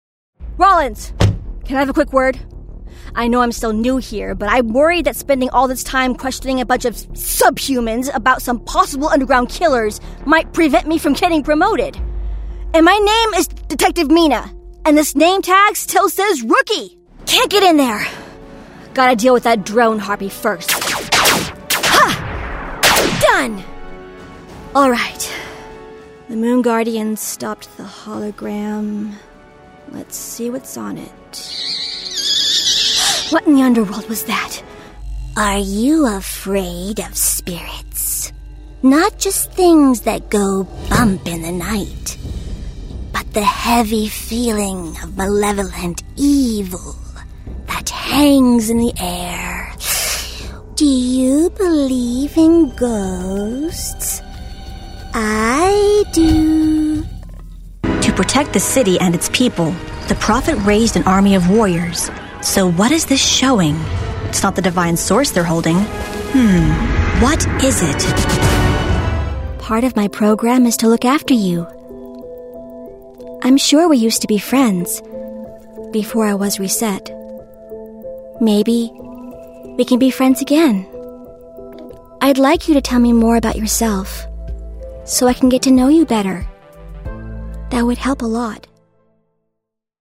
Young Adult, Adult
Has Own Studio
standard us | natural
GAMING 🎮